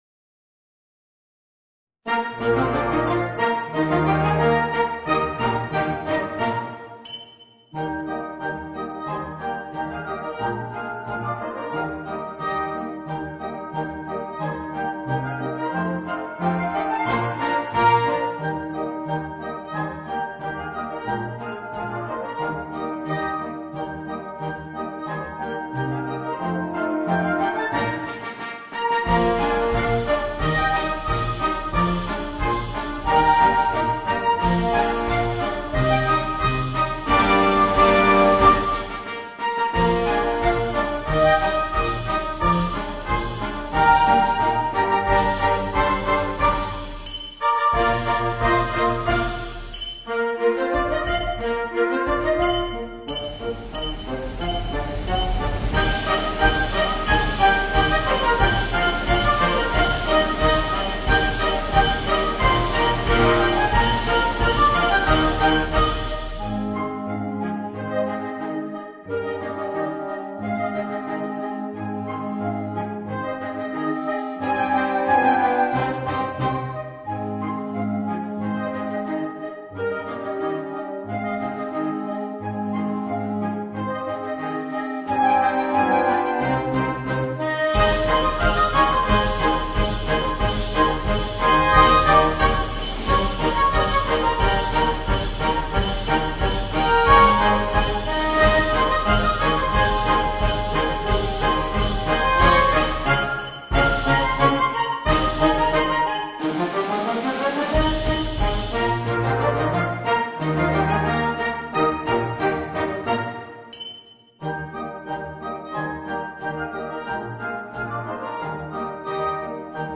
Polka francese, op. 269
MUSICA PER BANDA